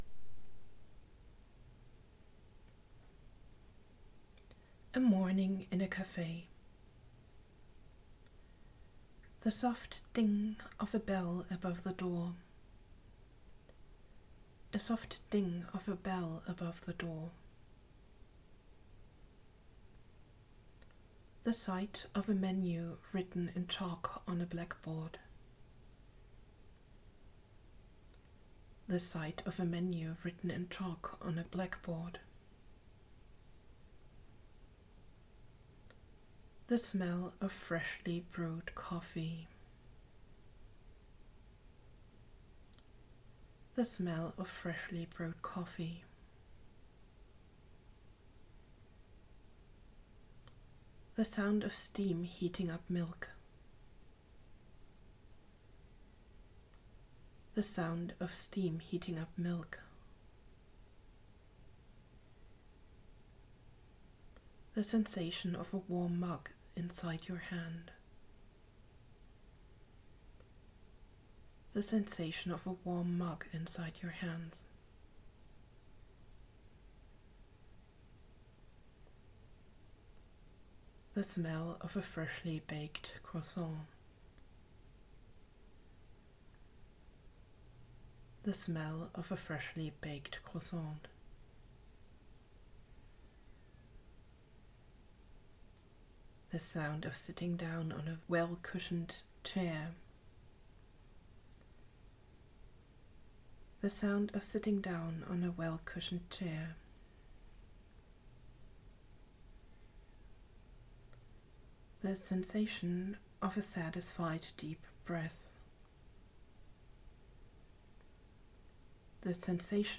by on in english, guided imagery
In this short-form approach you get instructions to focus on imagining one sensory experience after the other. The instruction is repeated twice and then you are asked to shift to the next picture or sensory experience immediately. It is supposed to be so fast-paced that you have no time to come up with negative ideas or elaborate inner stories.